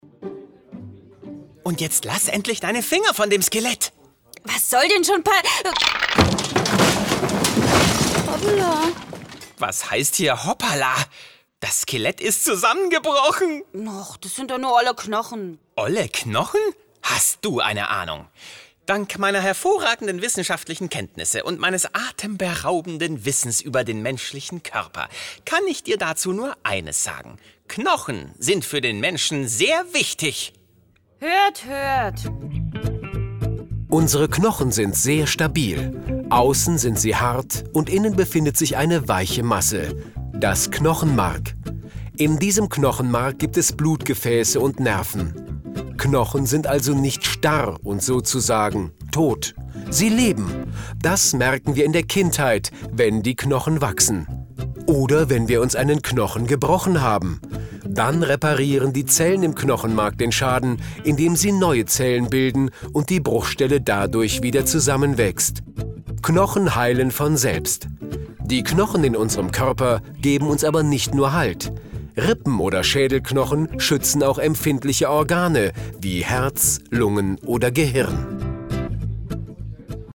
Das lustige Trio Theo, Tess und Quentin - ein wissbegieriges Fragezeichen, ein mutiges Ausrufezeichen und ein quirliger Punkt - führen auf unterhaltsame Weise durch die einzelnen Hörspiele. Die Hörspielbox "Junge Forscher" enthält folgende Sachthemen: unser Körper, Gehirn, Klima, Natur erforschen und schützen, Erfindungen und Bionik.